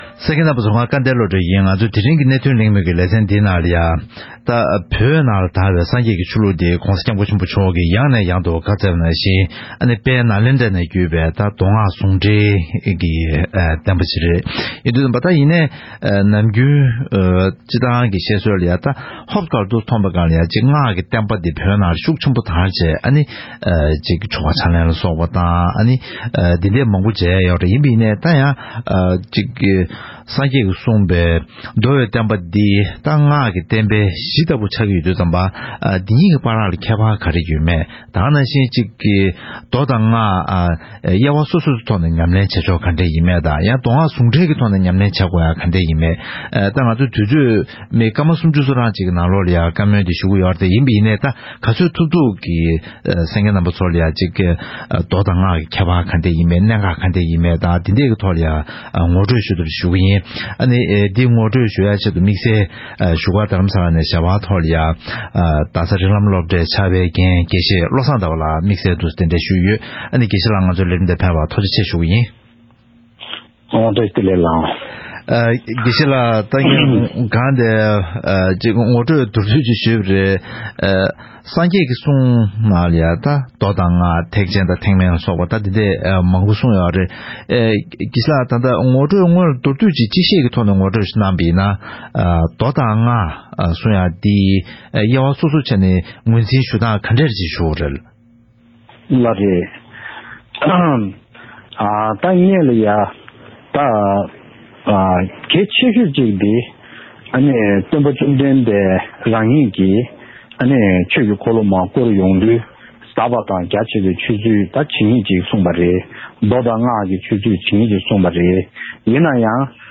ཐེངས་འདིའི་གནད་དོན་གླེང་མོལ་གྱི་ལེ་ཚན་དུ།